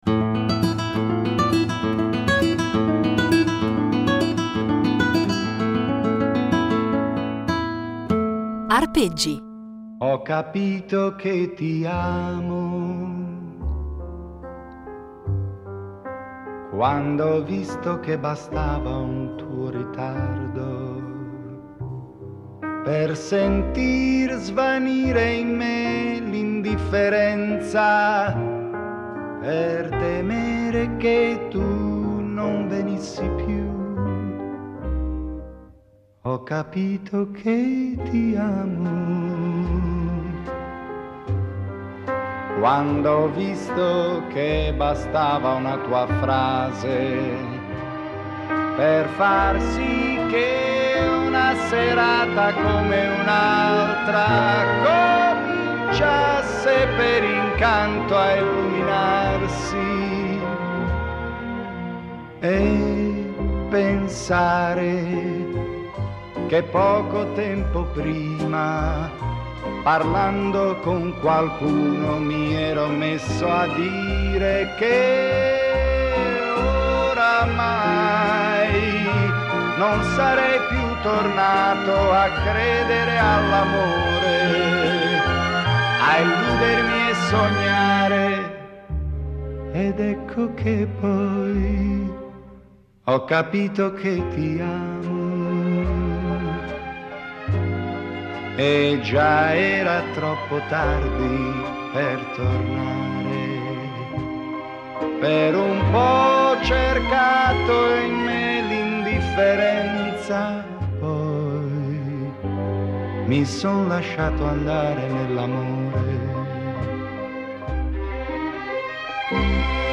sax
chitarra